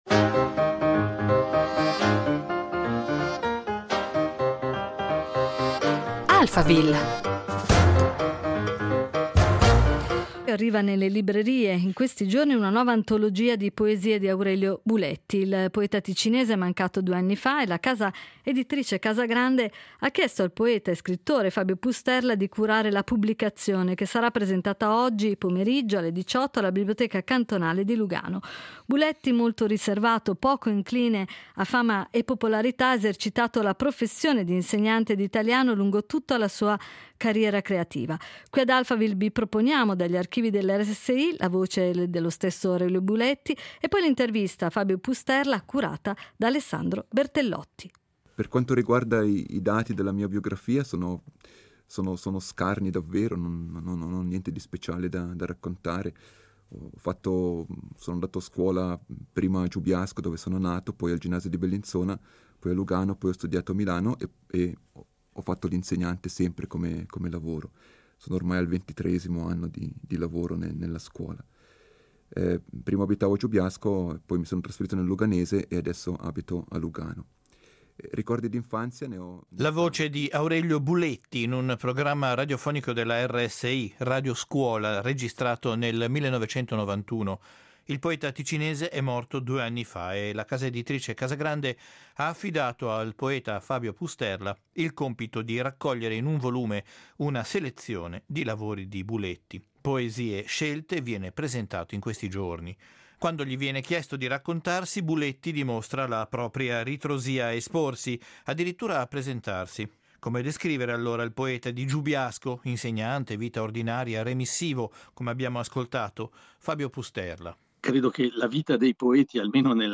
Ad Alphaville abbiamo avuto ospite Fabio Pusterla.